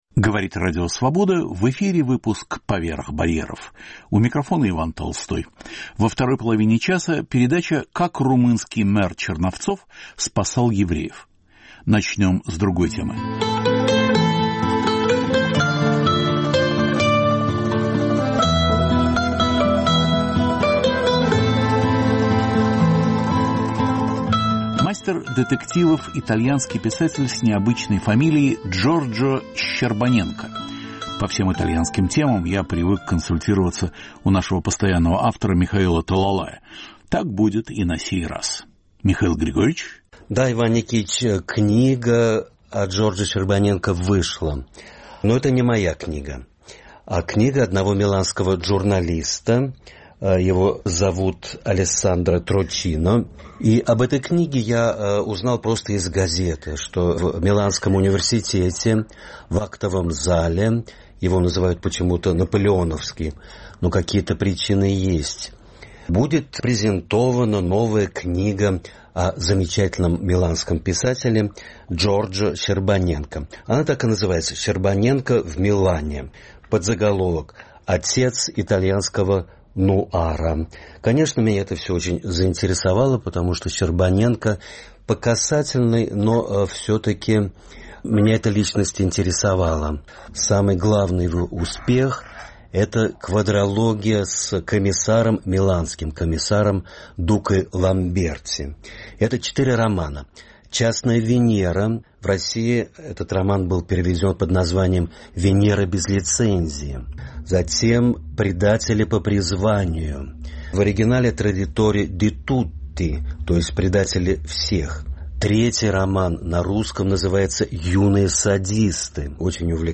Беседа о Черновцах.